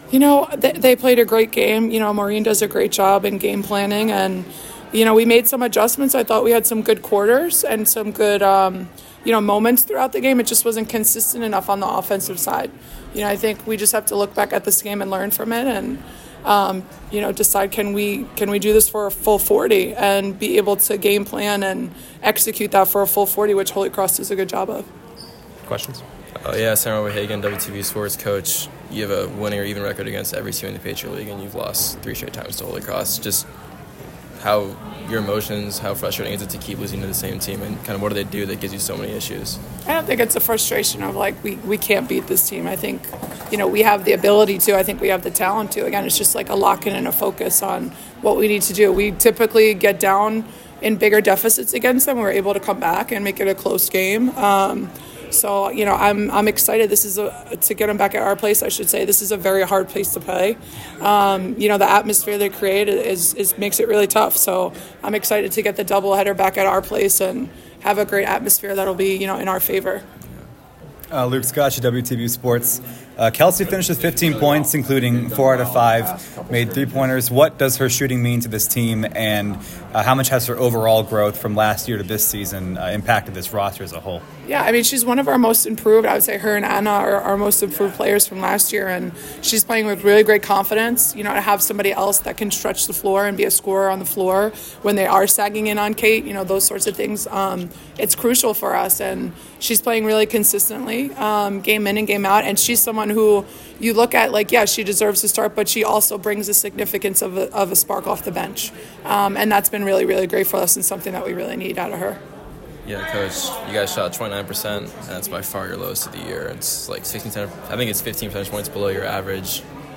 Holy Cross Postgame Press Conference
WBB_Holy_Cross_1_Postgame.mp3